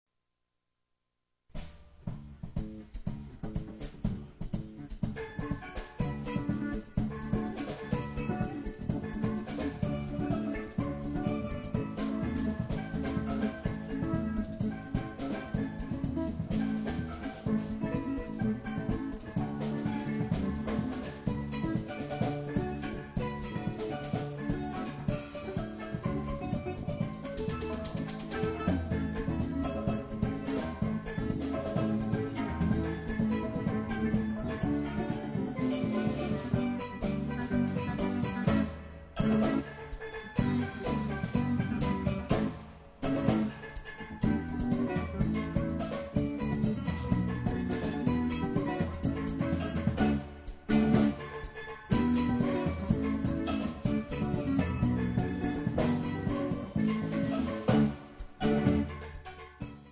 Saxes, flute, percussion, pan
Guitars
Lead pans, percussion
Bass
Drums